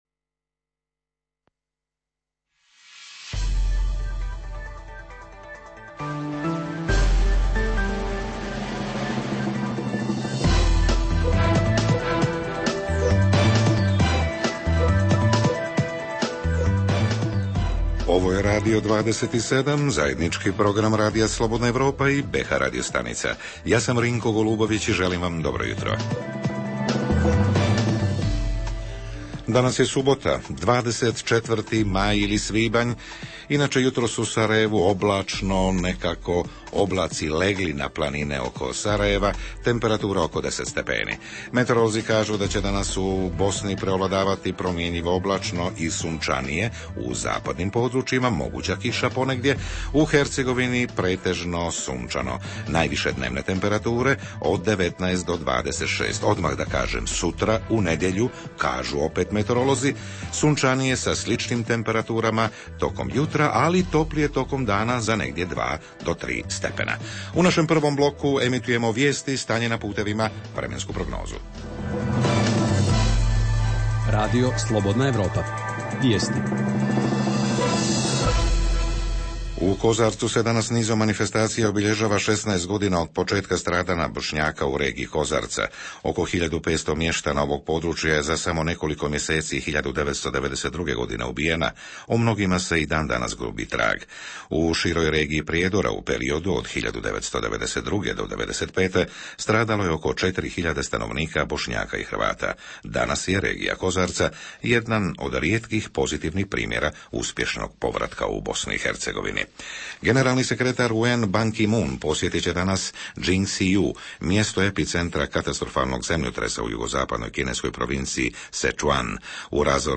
Jutarnji program za BiH koji se emituje uživo. Govorimo o ekologiji, kuturi i naravno, o Eurosongu i mogućim pobjednicima.
Redovni sadržaji jutarnjeg programa za BiH su i vijesti i muzika.